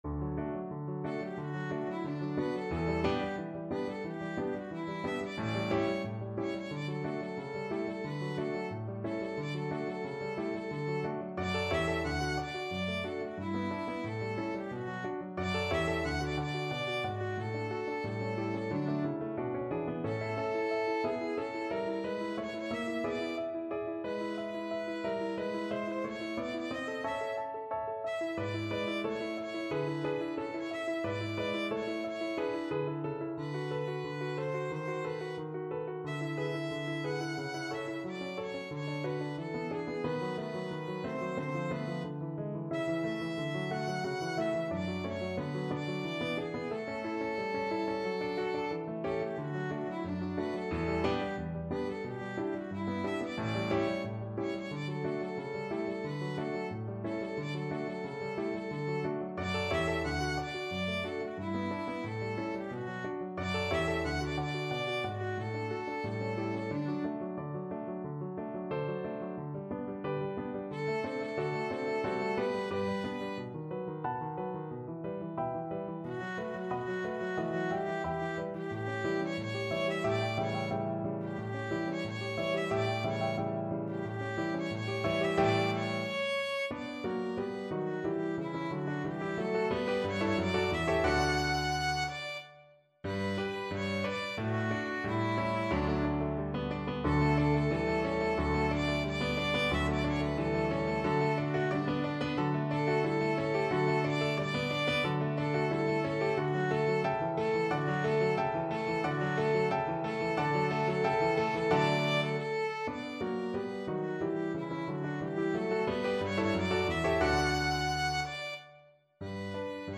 Play (or use space bar on your keyboard) Pause Music Playalong - Piano Accompaniment Playalong Band Accompaniment not yet available transpose reset tempo print settings full screen
Violin
2/2 (View more 2/2 Music)
D major (Sounding Pitch) (View more D major Music for Violin )
Allegro vivace =90 (View more music marked Allegro)
Classical (View more Classical Violin Music)